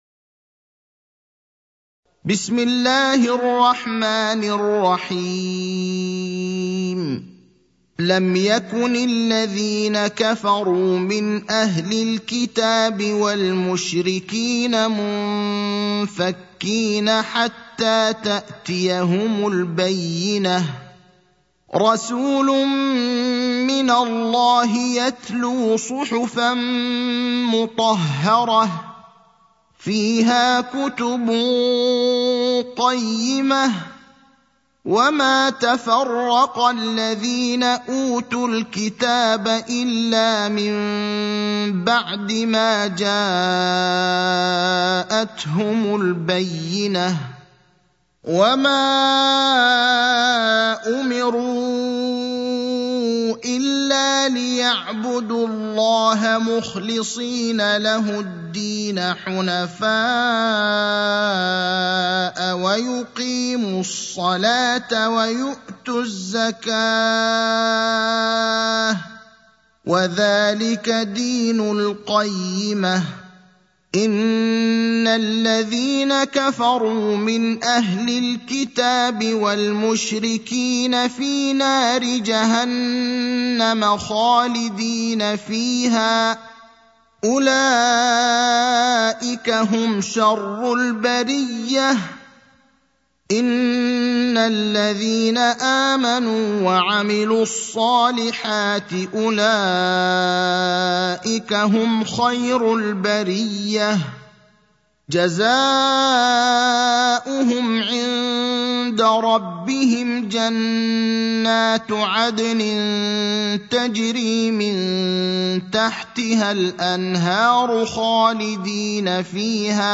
المكان: المسجد النبوي الشيخ: فضيلة الشيخ إبراهيم الأخضر فضيلة الشيخ إبراهيم الأخضر البينة (98)